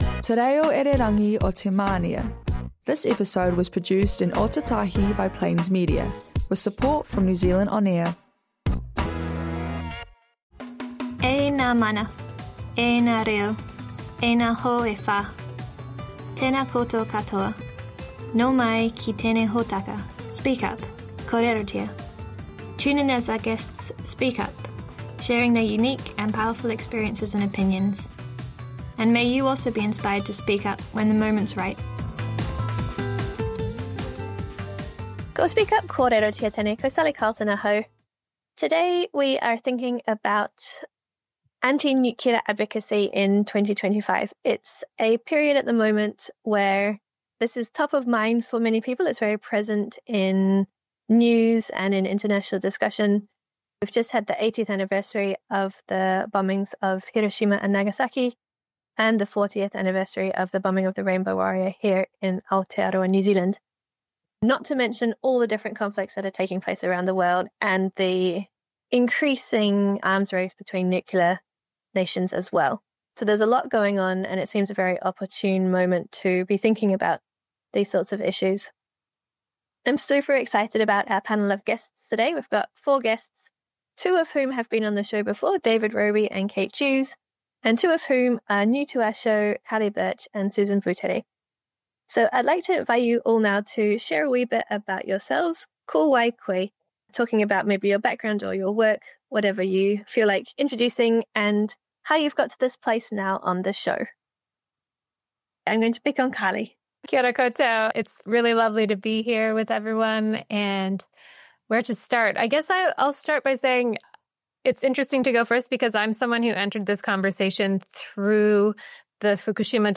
Christchurch City Libraries blog hosts a series of regular podcasts from specialist human rights radio show Speak up - Kōrerotia.